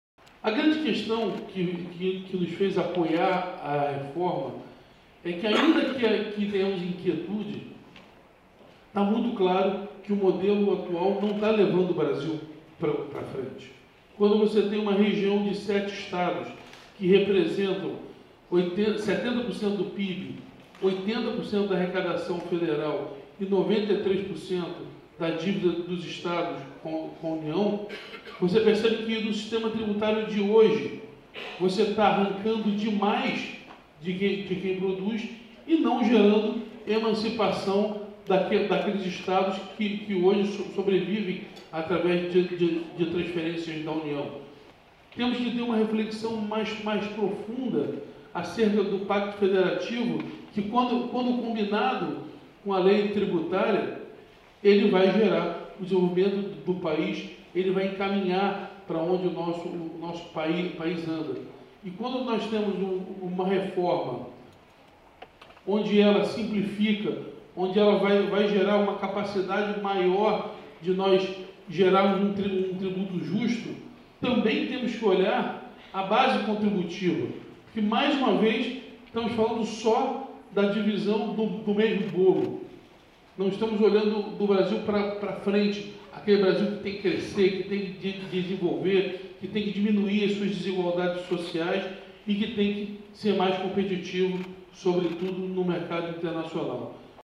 O governador Cláudio Castro participou, na manhã desta segunda-feira (21/08), do seminário Reflexões sobre a Reforma Tributária, promovido pela Fundação Getulio Vargas (FGV), no Rio de Janeiro.
SonoraSeminarioReflexoes.mp3